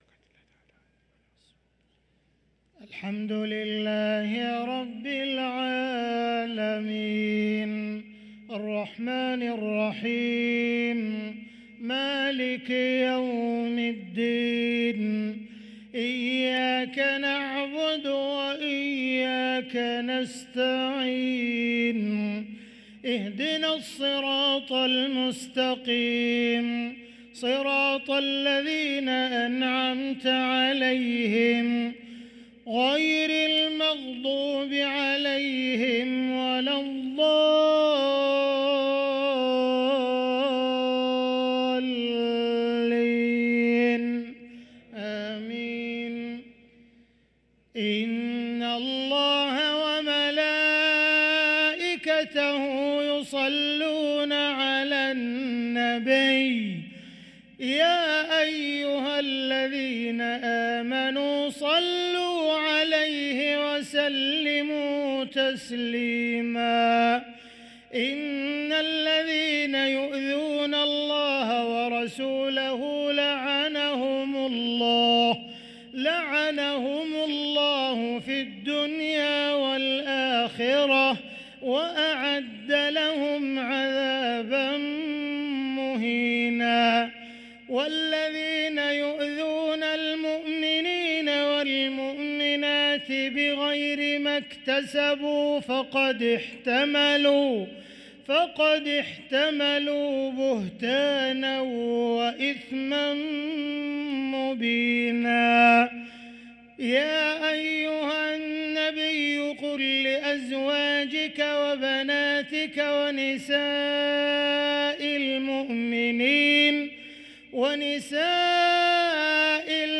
صلاة العشاء للقارئ عبدالرحمن السديس 8 صفر 1445 هـ
تِلَاوَات الْحَرَمَيْن .